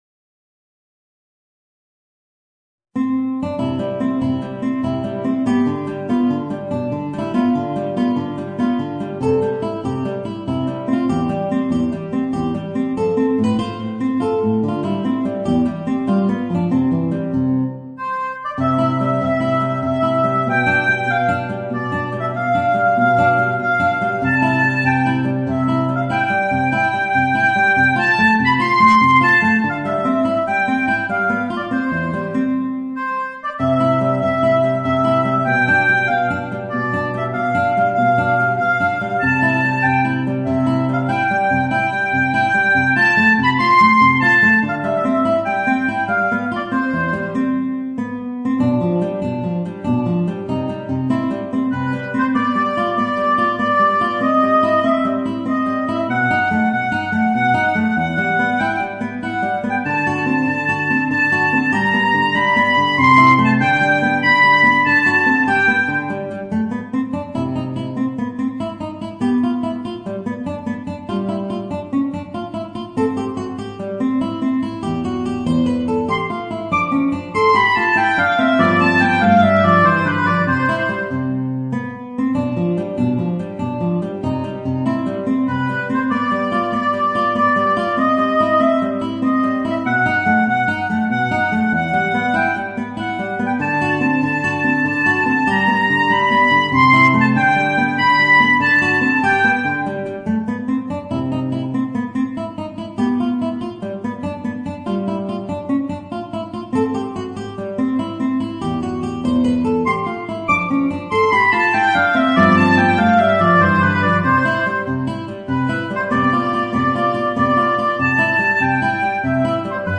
Voicing: Oboe and Guitar